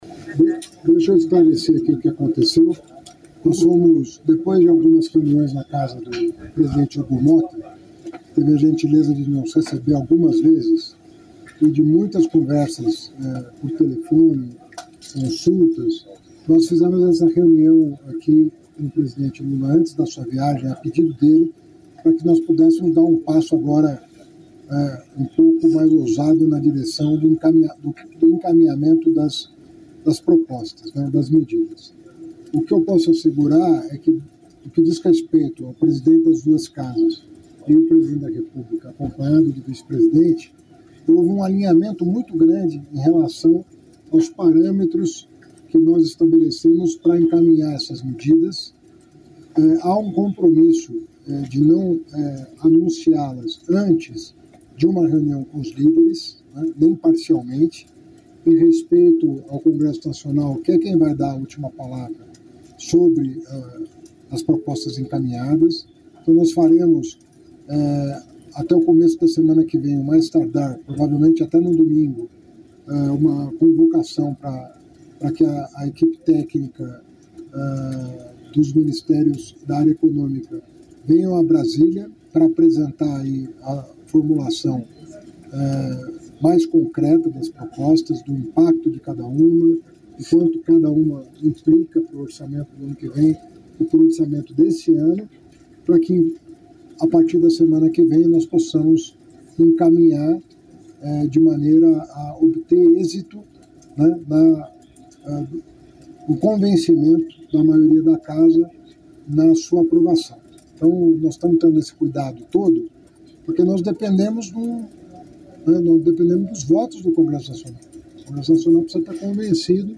Em discurso durante a cerimônia de assinatura do Pacto pela Transformação Ecológica entre os Três Poderes, a ministra do Meio Ambiente e Mudança do Clima, Marina Silva, ressaltou o desafio que o Brasil tem pela frente ao assumir o compromisso de defender o meio ambiente.